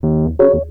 04 Rhodes 14.wav